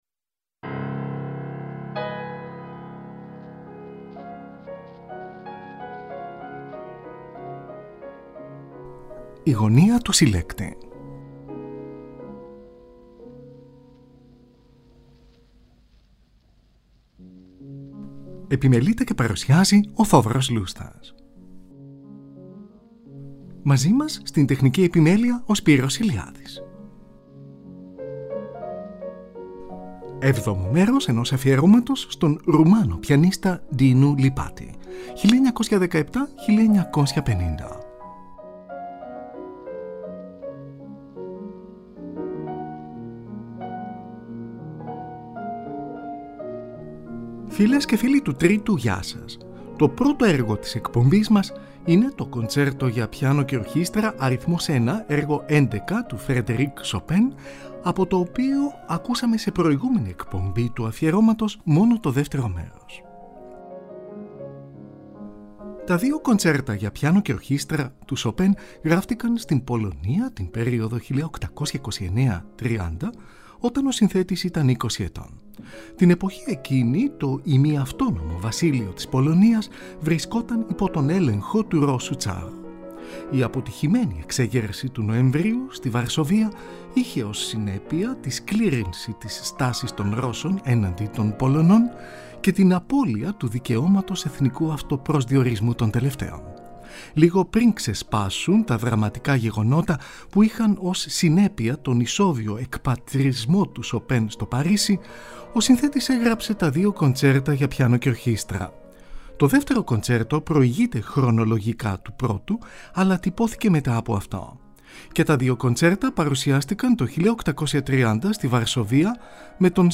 Dinu Lipatti: Concertino σε κλασικό ύφος, για πιάνο και ορχήστρα, έργο 3. Σολίστ είναι ο ίδιος ο Lipatti, με άγνωστη ορχήστρα και μαέστρο, από ζωντανή ηχογράφηση, εν έτει 1948.